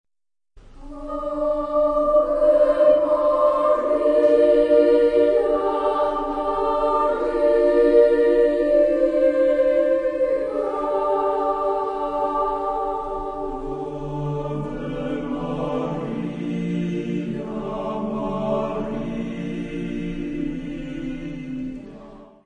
Genre-Style-Form: Sacred
Type of Choir: SSAATTBB  (8 mixed voices )
Tonality: D minor